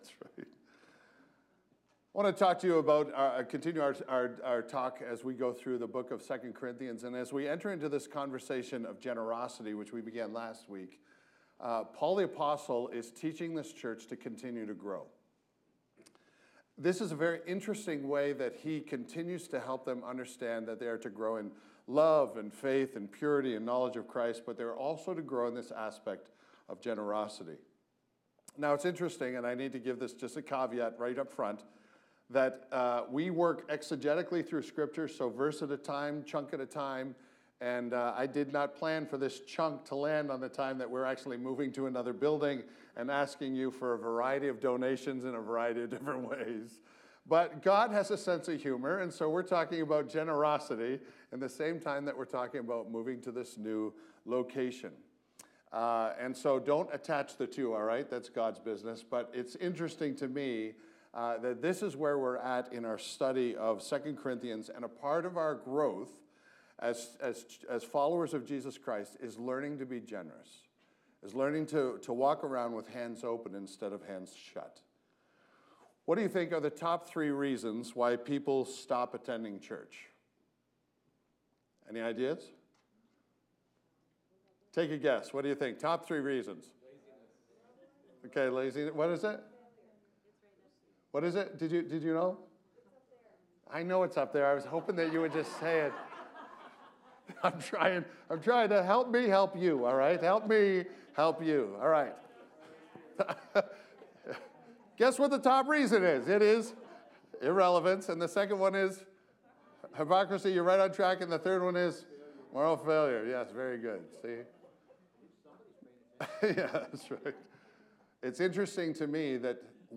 Village Church East: Sermons